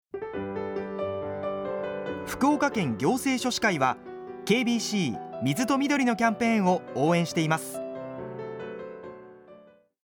KBC「水と緑のキャンペーン」ラジオCM放送
福岡県行政書士会では、８月４日から９月３０日まで、KBCラジオにてスポットCMを放送しています。